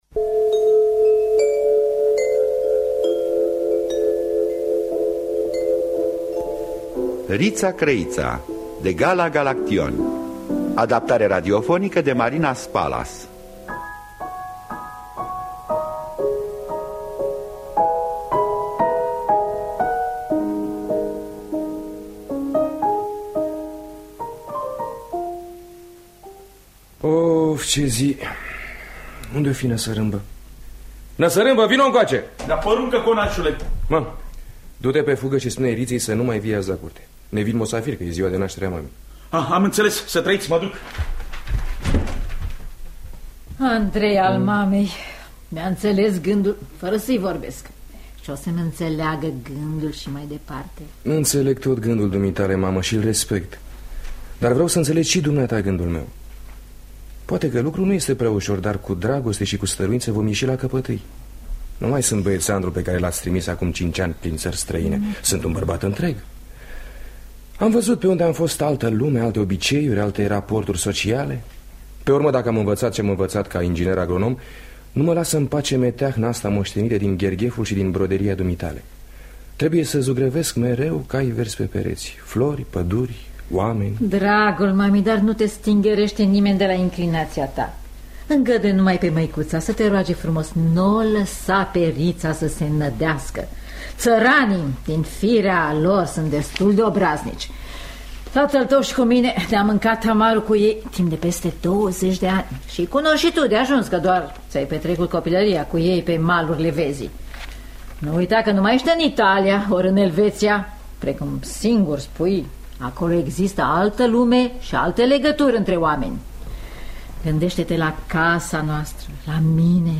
Adaptarea radiofonică de Marina Spalas.